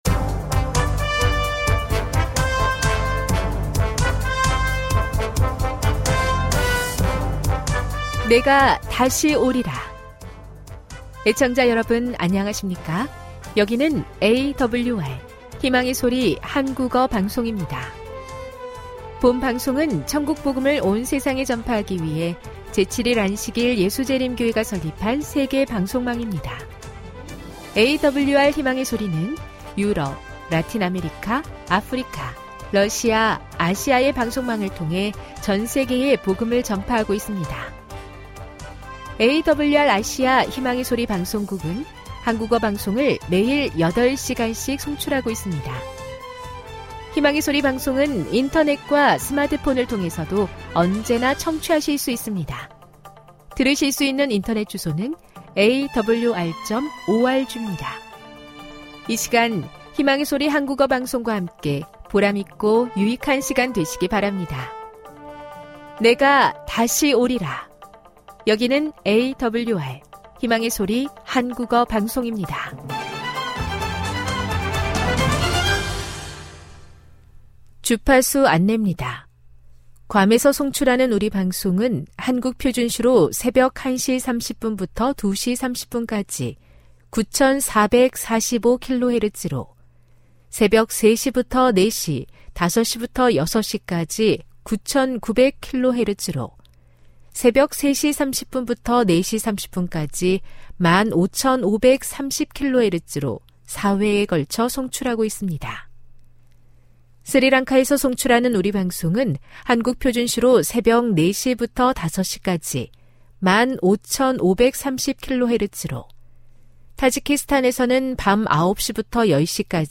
1 설교,건강한생활, 만나,명상의 오솔길 58:13